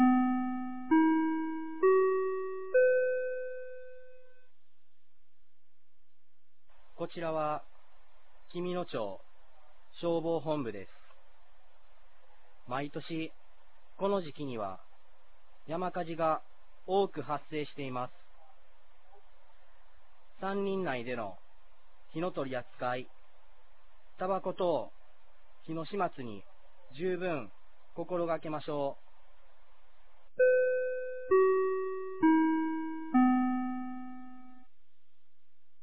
2022年05月21日 16時00分に、紀美野町より全地区へ放送がありました。
放送音声